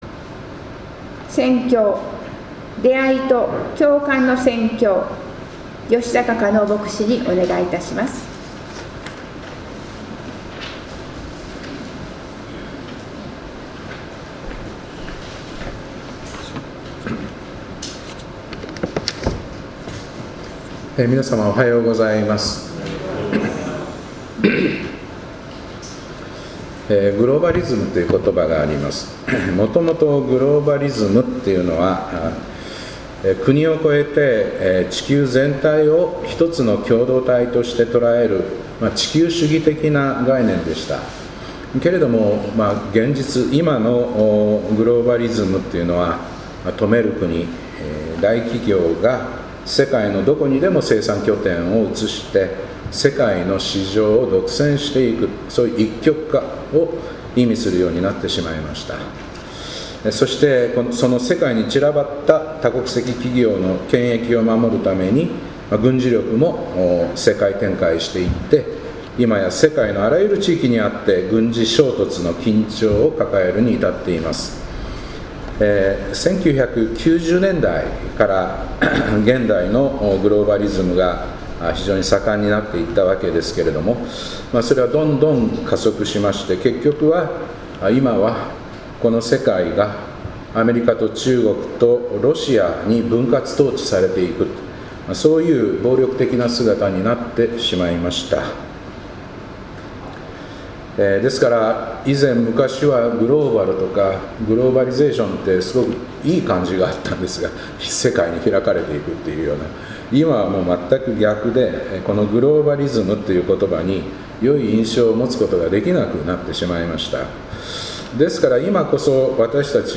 2025年11月30日礼拝「出会いと共感の宣教」